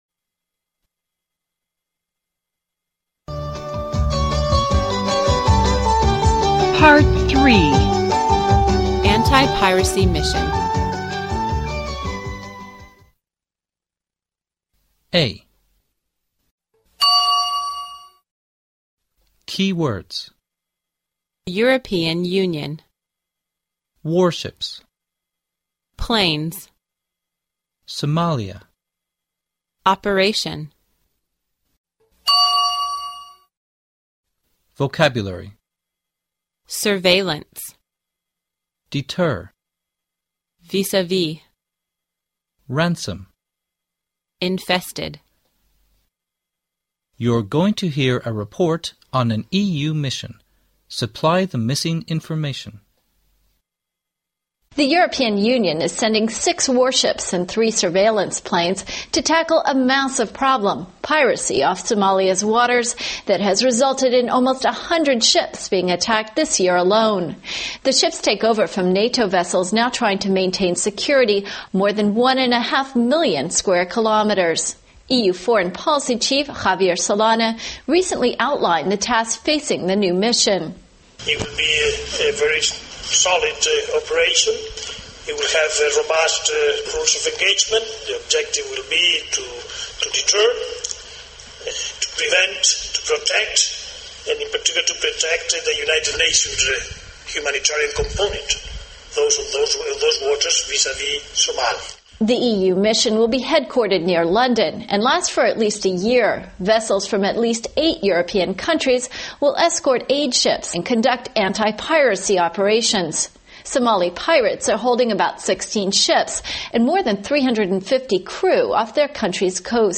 You're going to hear a report on an EU mission.